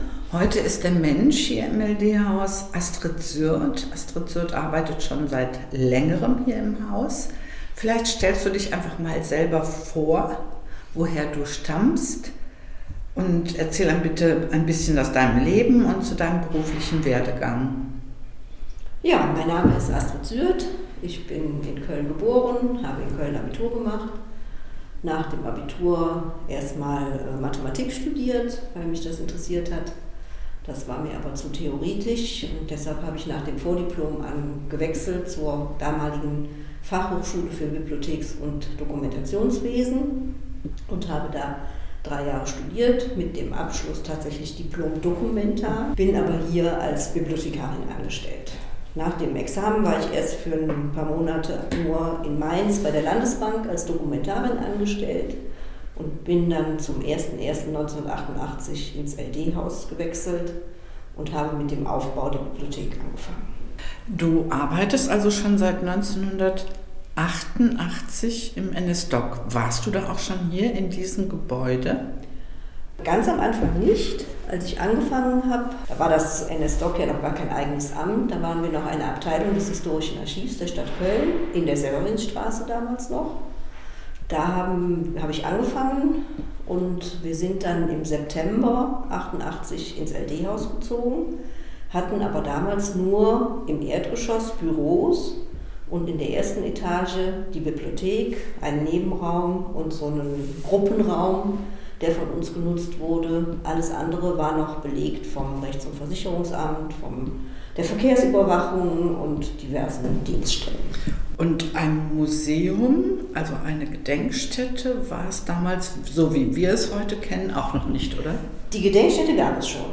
Gespräche mit Menschen im EL-DE-Haus